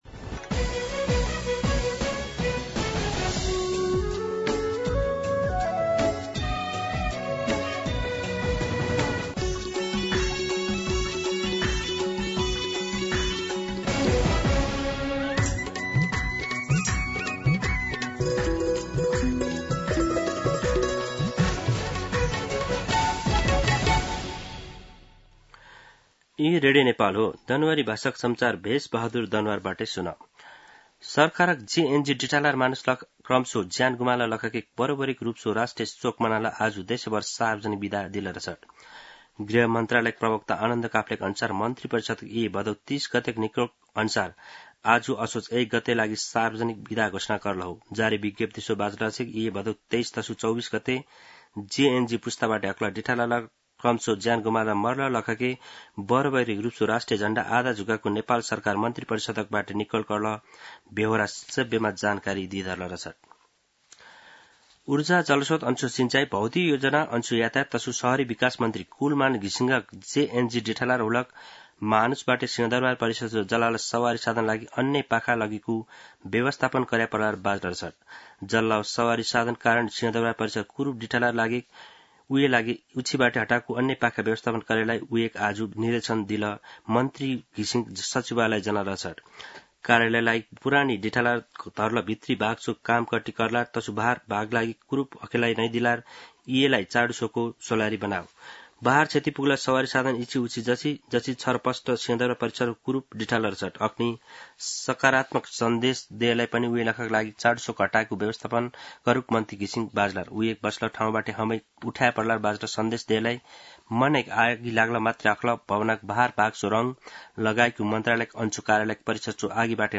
दनुवार भाषामा समाचार : १ असोज , २०८२